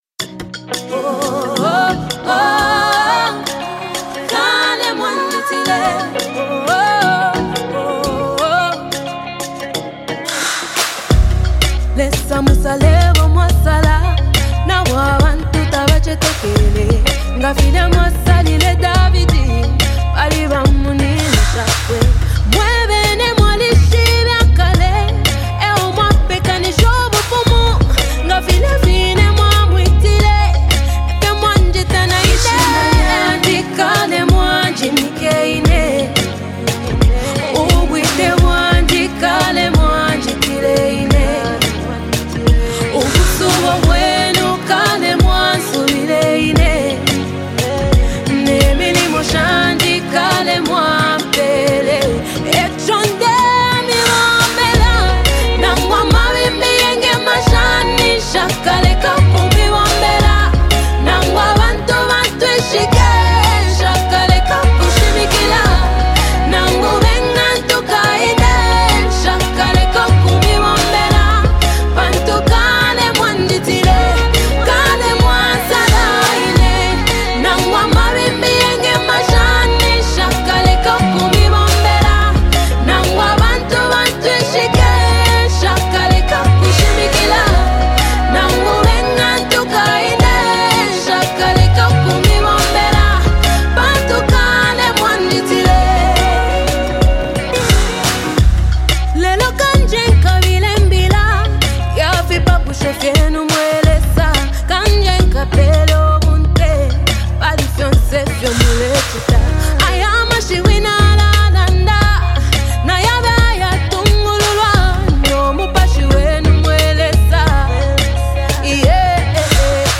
Gospel Music
coupled with a soothing instrumental arrangement
If you are a fan of uplifting and spirit-filled gospel music